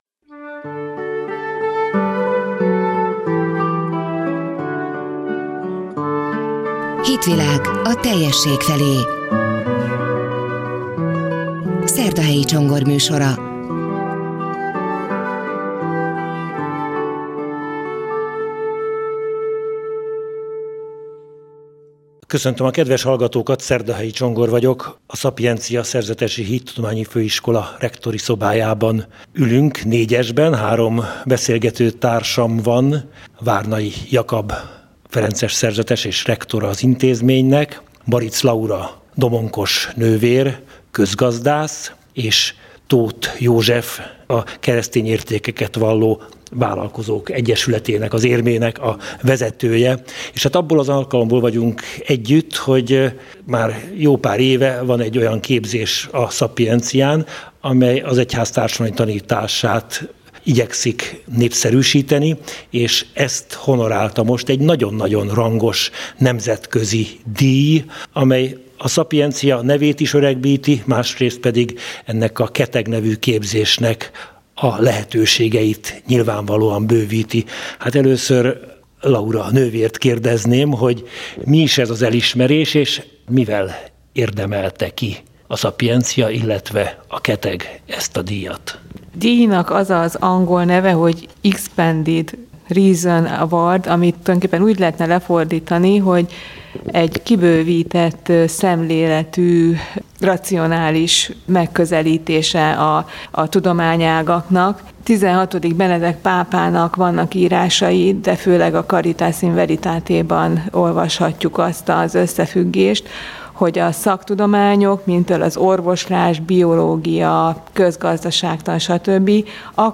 Komoly elismerésben részesült a KETEG, vagyis Keresztény Társadalmi Elvek a Gazdaságban képzés, amely a Sapientia Szerzetesi Hittudományi Főiskola keretében zajlik. A Jozeph Ratzinger - XVI. Benedek nevét viselő alapítvány 30 ország 170 egyeteméről érkezett 367 pályamű közül magyar pályázatnak ítélte a két első díj egyikét, melyet szeptember 27-én adnak át a Vatikánban. Ebből az alkalomból készített beszélgetést a Lánchíd Rádió